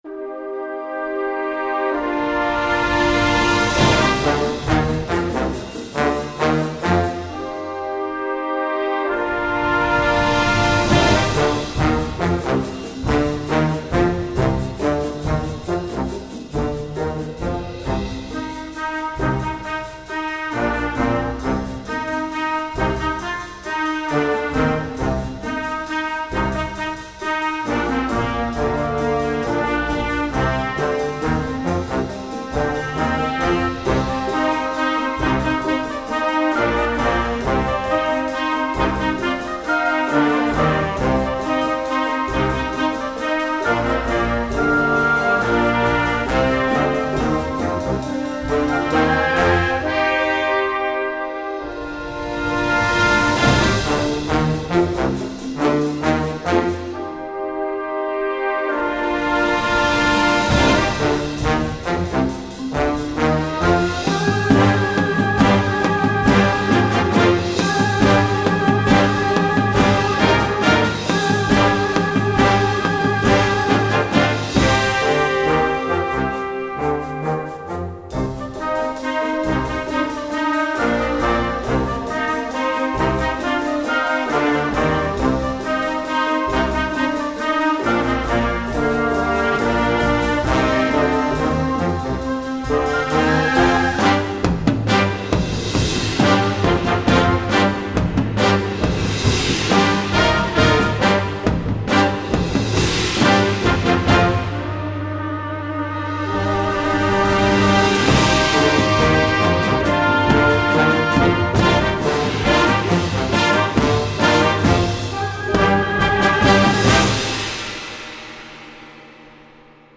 Besetzung: Blasorchester
einem aufregenden Werk für Anfängerorchester